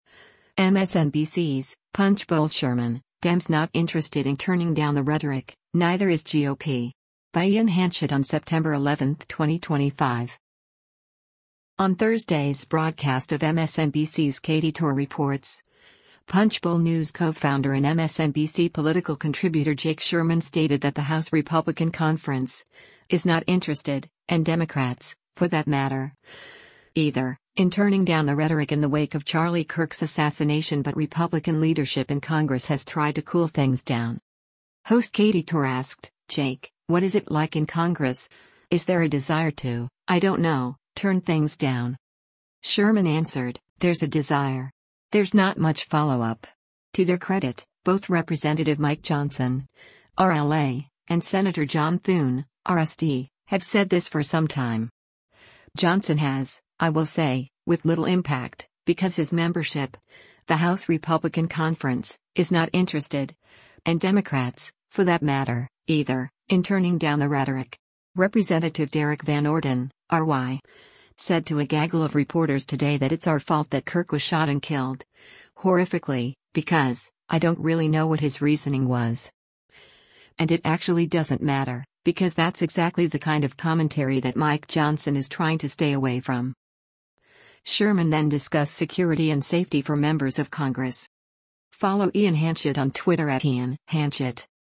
Video Source: MSNBC